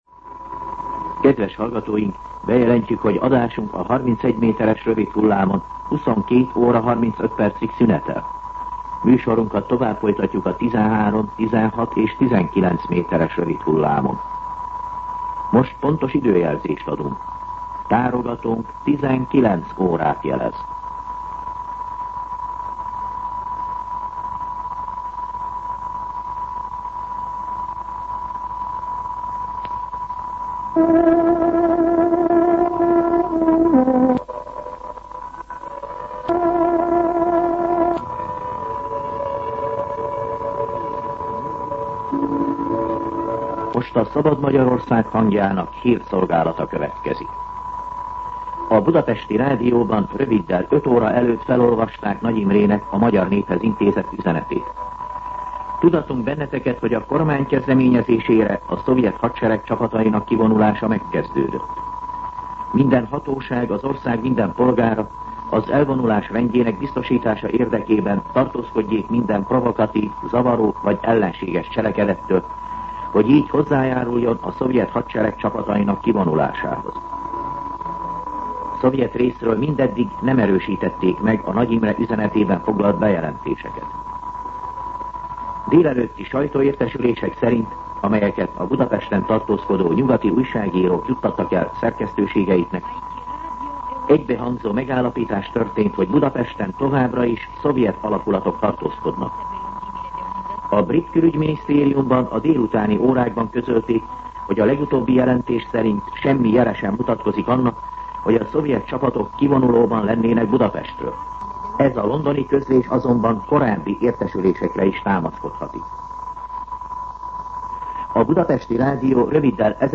19:00 óra. Hírszolgálat